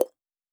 Click (23).wav